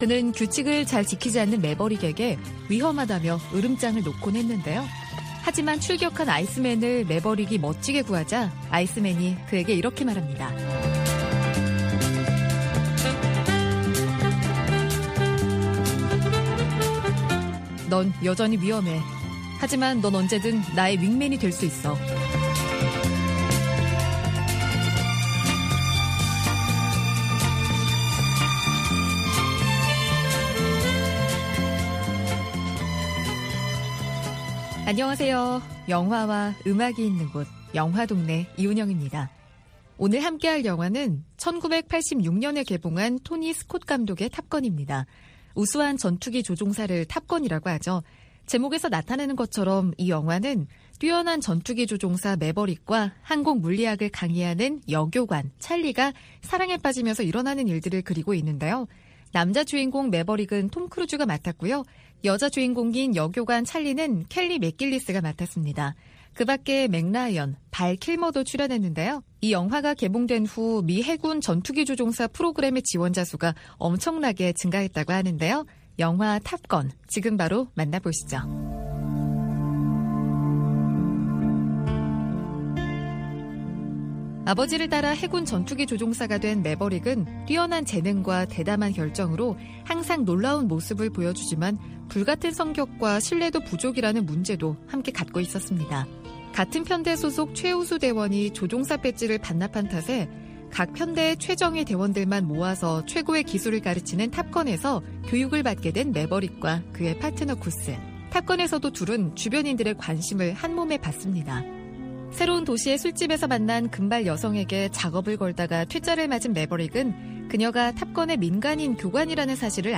VOA 한국어 방송의 일요일 오전 프로그램 2부입니다. 한반도 시간 오전 5:00 부터 6:00 까지 방송됩니다.